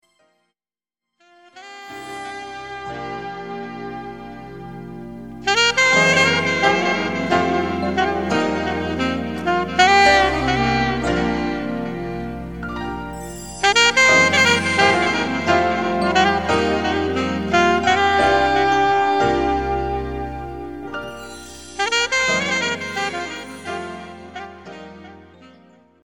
Piano-Saxophone